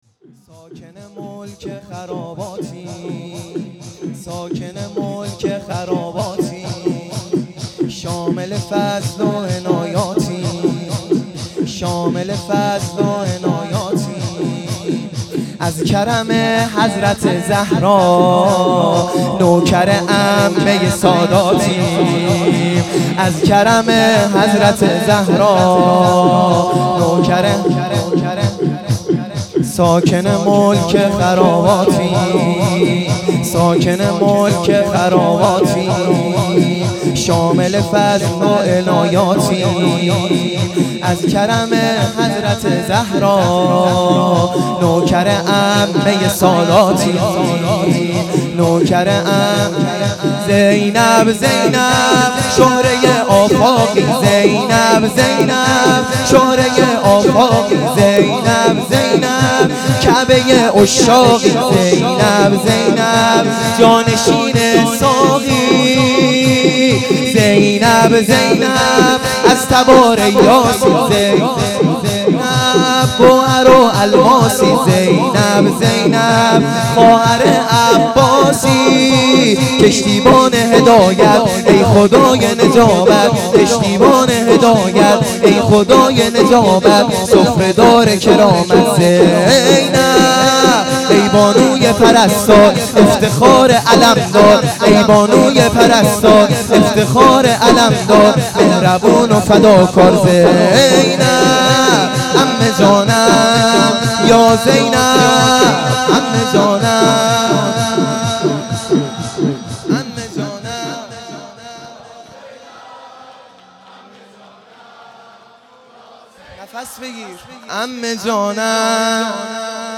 شور | ساکن ملک خراباتیم